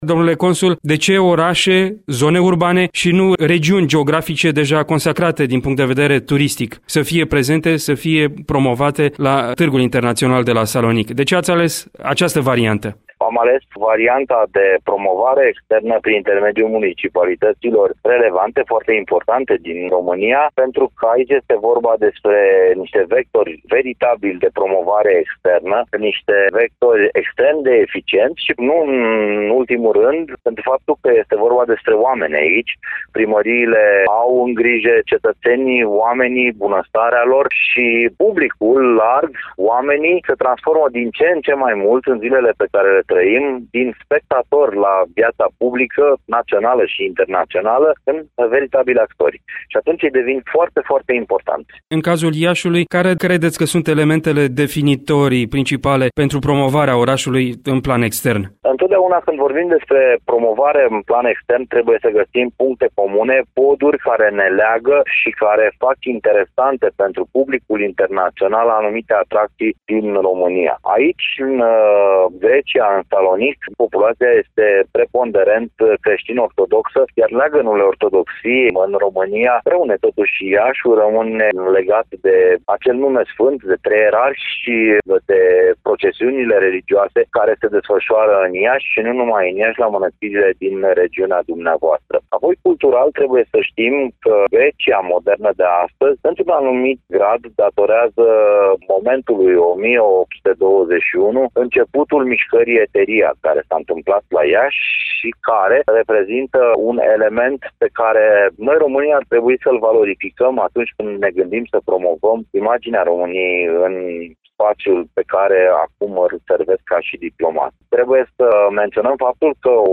La emisiunea ”Sens Unic”, domnul Dan Dima, Consul al României la Salonic, a oferit câteva răspunsuri:
Un interviu